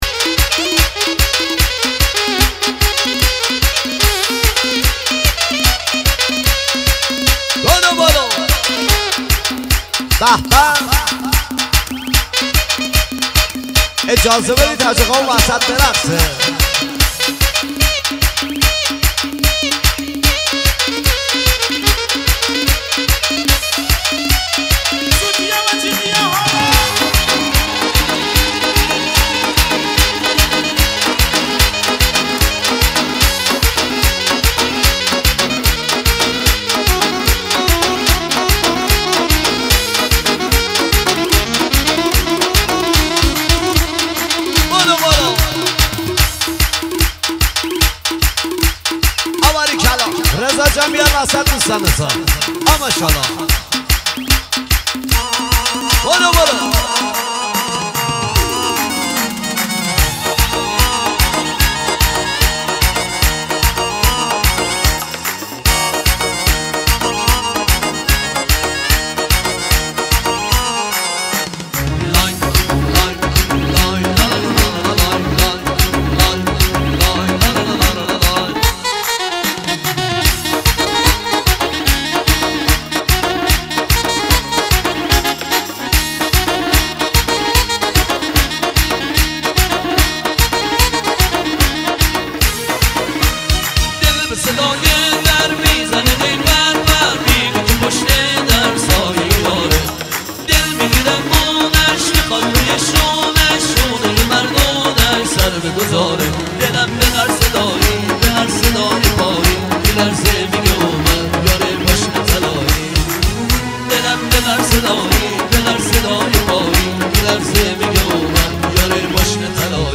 محلی